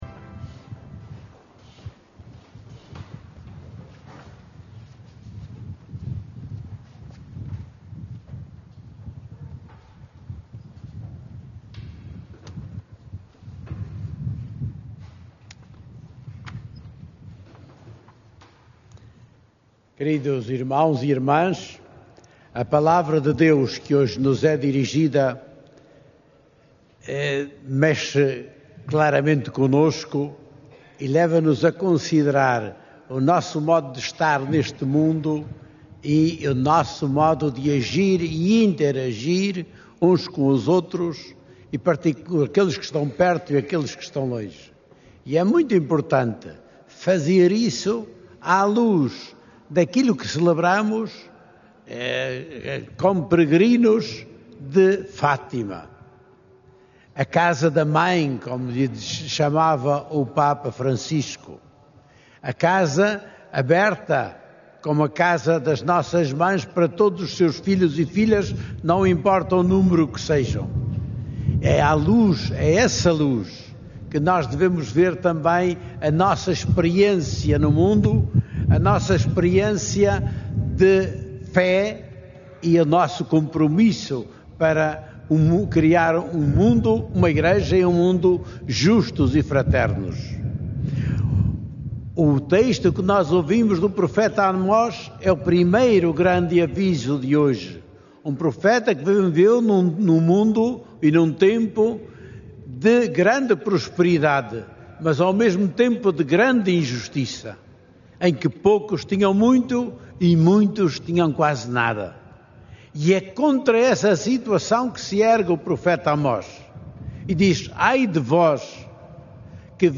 Na homilia que proferiu esta manhã, no Recinto de Oração, o bispo de Leiria-Fátima incentivou os peregrinos a uma conduta generosa e fraterna e não apenas de caridade.